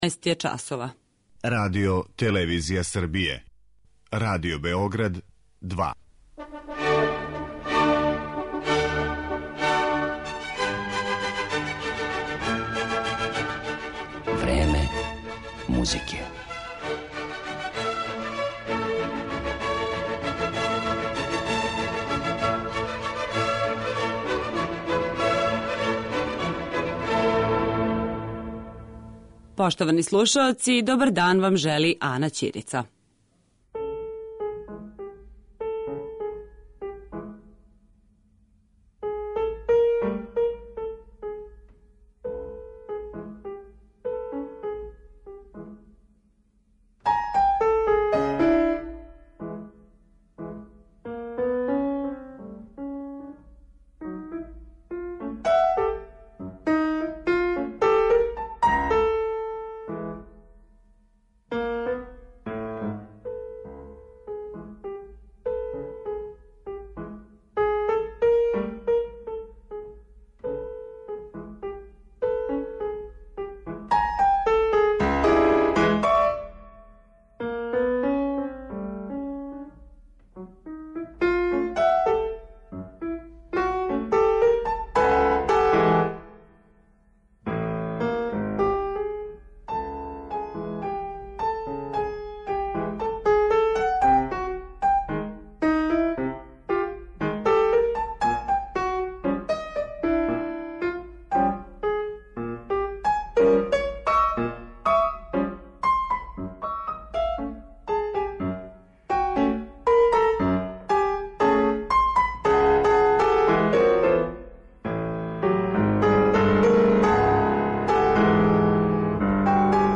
Гост емисије